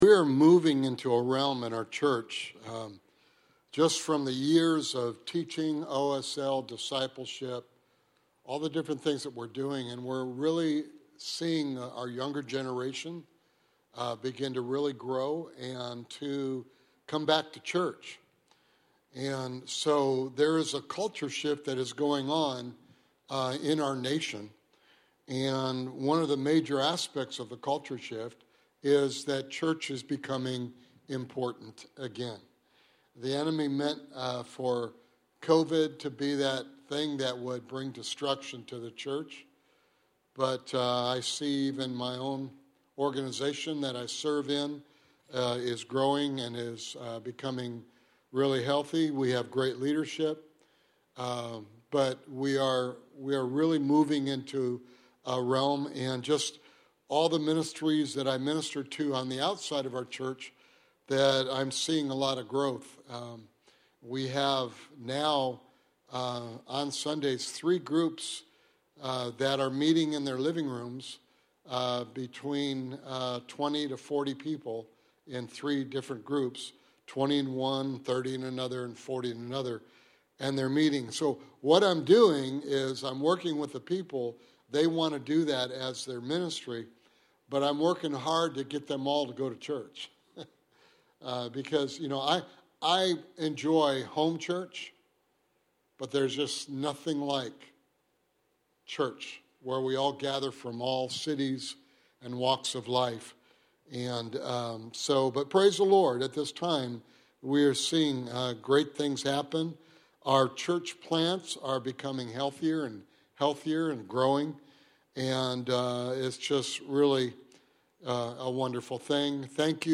Sermon Series: The Cross & The Believer